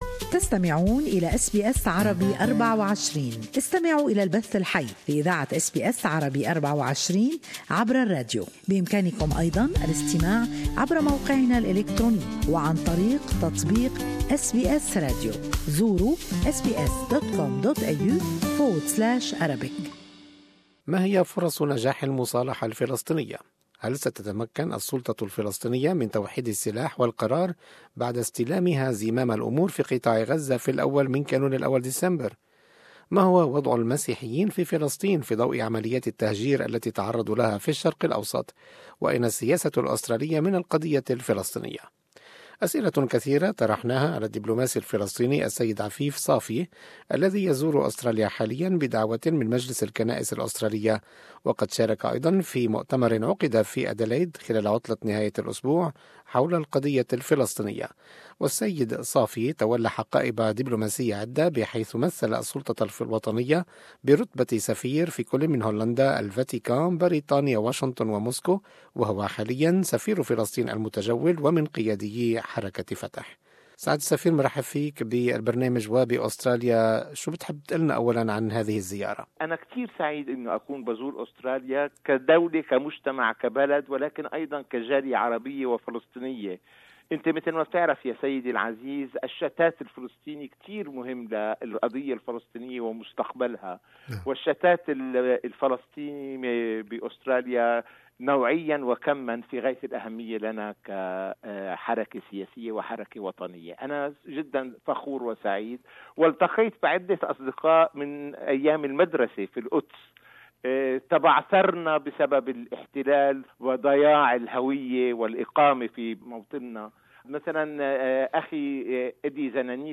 Interview with visiting Palestinian diplomat Afif Safia about the prospects of peace, reconciliation and the two-state solution.